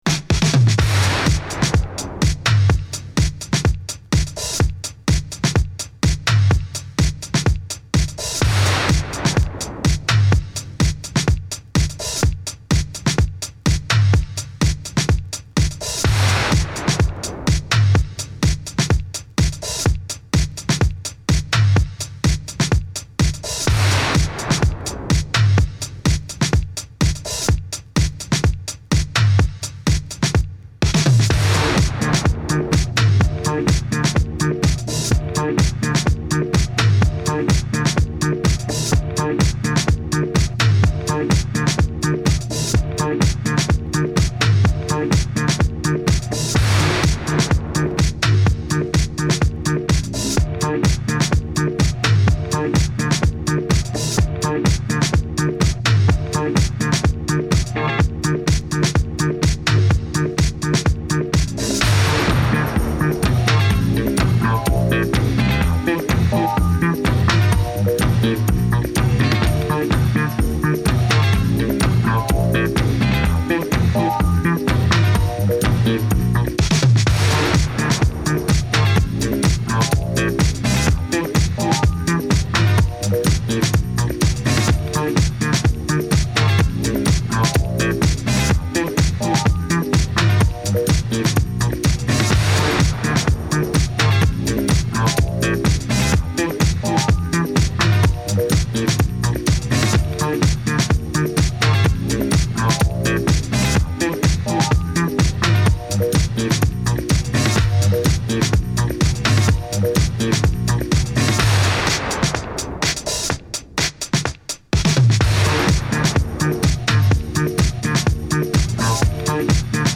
TOP > House / Techno > VARIOUS